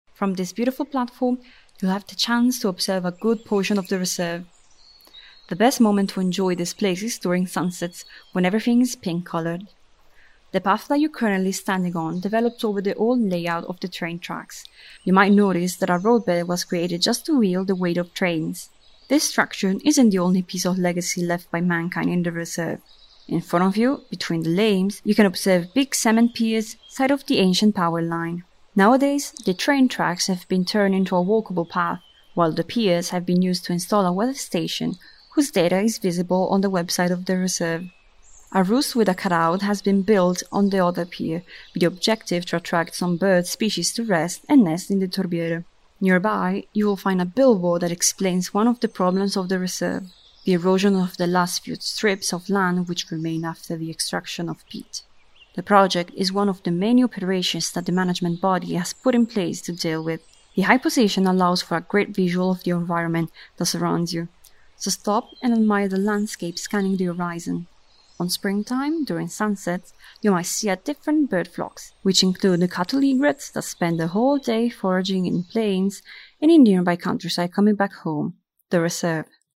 Audioguide 10